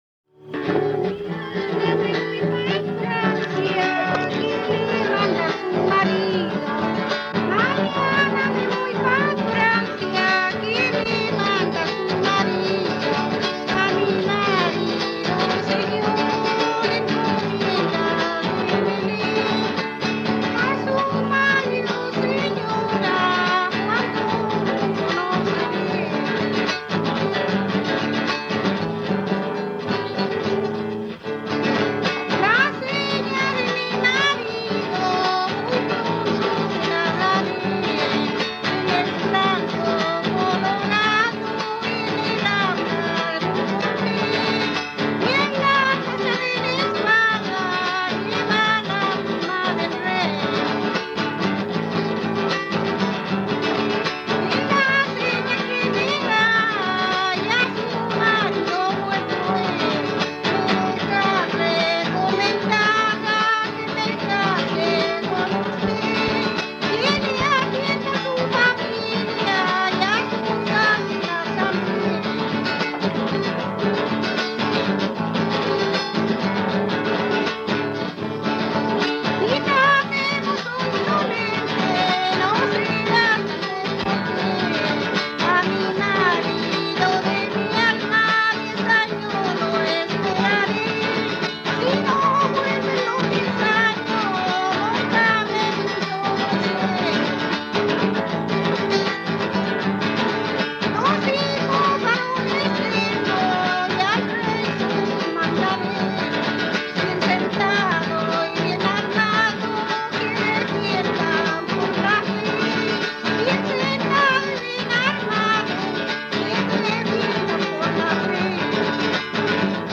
Romance en forma de tonada.
quien se acompaña con una guitarra afinada con la tercera alta.
Música tradicional
Folklore